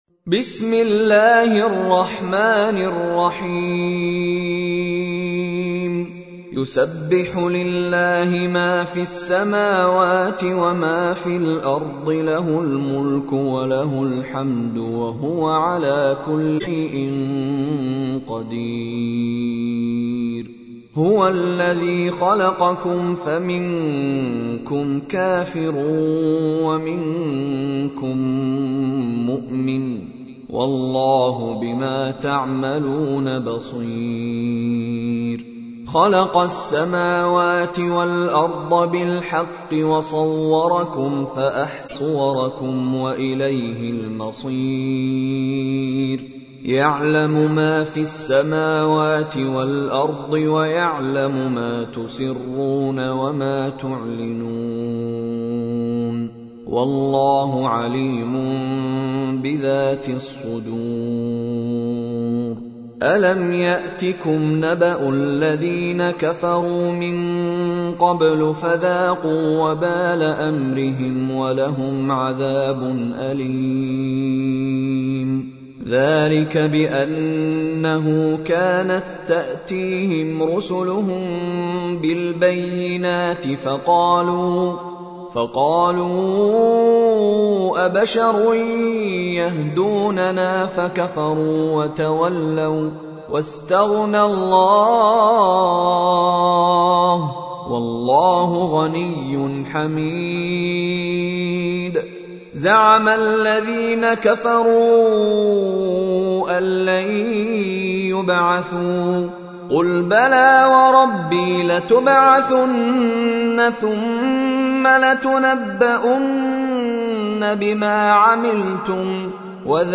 تحميل : 64. سورة التغابن / القارئ مشاري راشد العفاسي / القرآن الكريم / موقع يا حسين